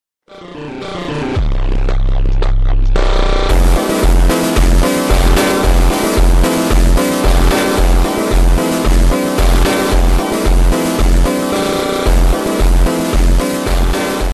Moore oklahoma EF5 tornado edit